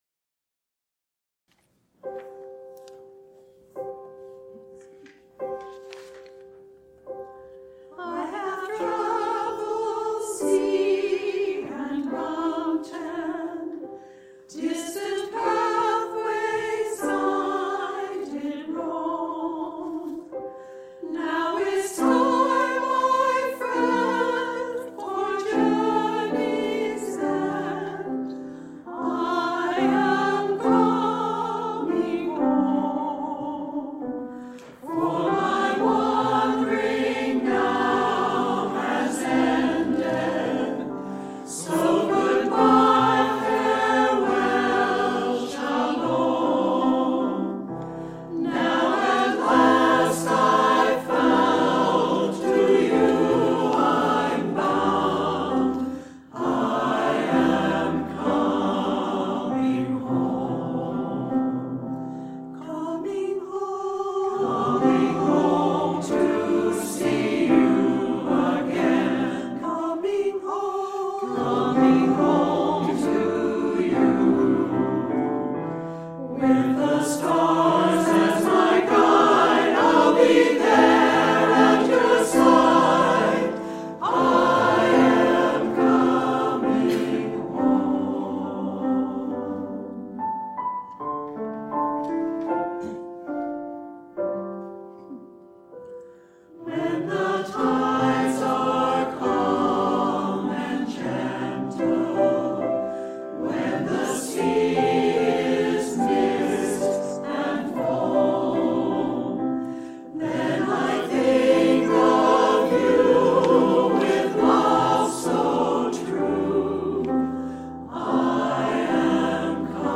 Past QUUF Choir Recordings